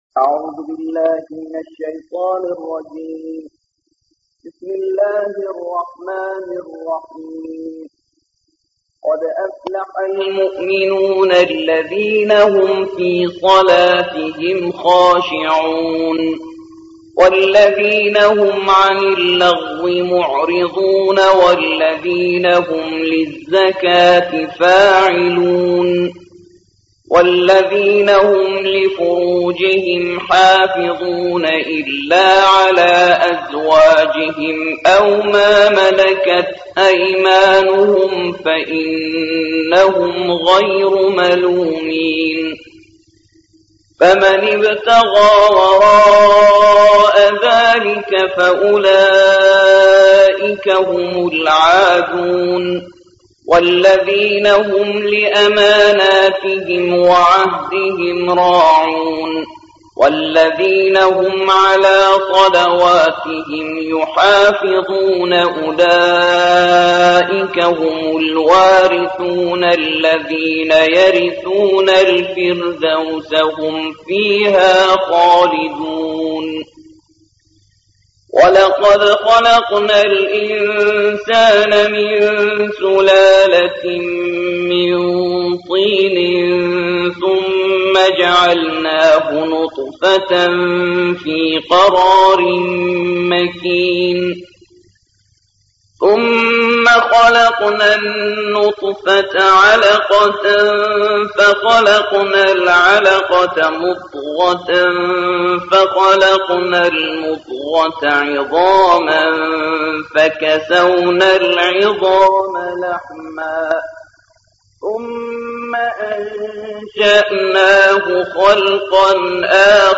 23. سورة المؤمنون / القارئ